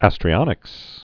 (ăstrē-ŏnĭks)